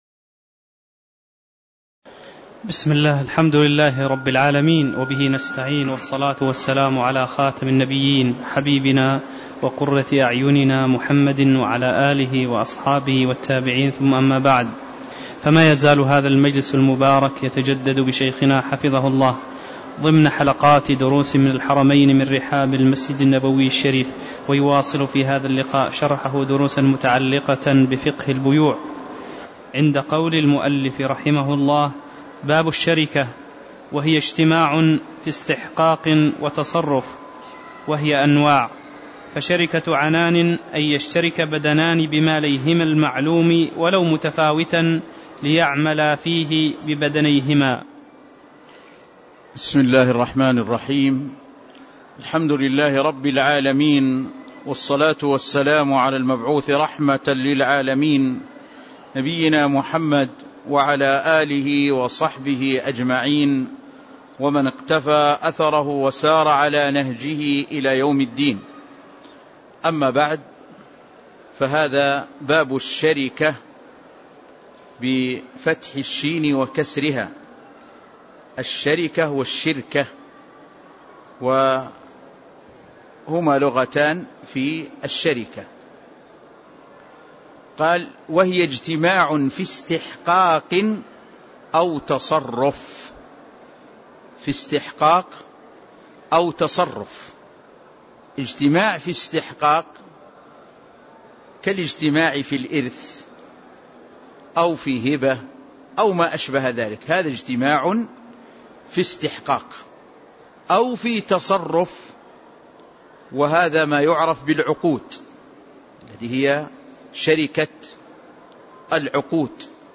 تاريخ النشر ٢٨ محرم ١٤٣٧ هـ المكان: المسجد النبوي الشيخ